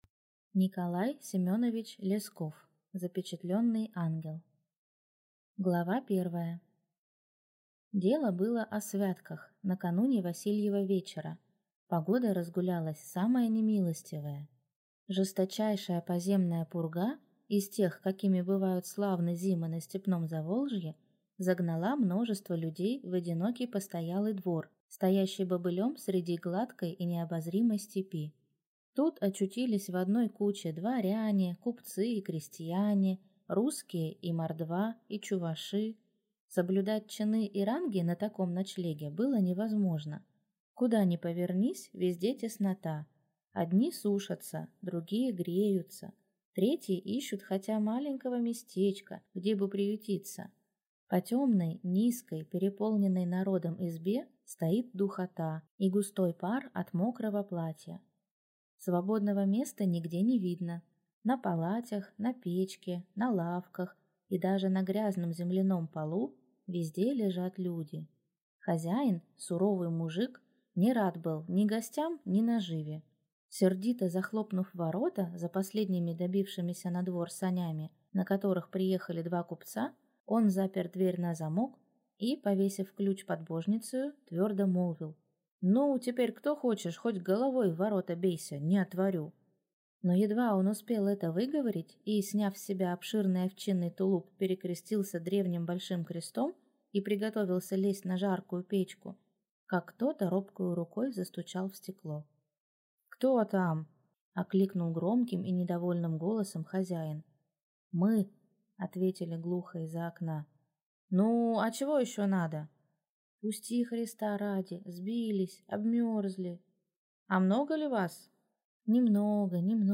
Аудиокнига Запечатленный ангел | Библиотека аудиокниг